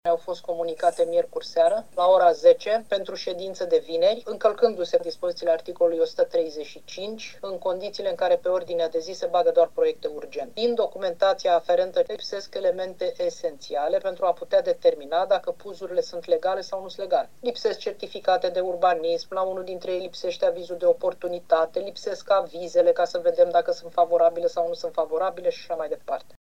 Proiectul care prevederea construirea unui ansamblu rezidențial cu mii de locuințe pe terenul fostei baze RATC din Constanța a fost respins, din nou vineri, prin vot majoritar, în ședința de Consiliu Local.
Felicia Ovanesian a mai susținut că proiectul inițiat de firma Cambella Prod, aflată în spatele investiției și de primarul Vergil Chițac, nu a respectat prevederile legale și a fost introdus în mod abuziv pe ordinea de zi, printr-o procedura destinată doar proiectelor care reprezintă urgențe: